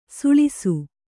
♪ suḷisu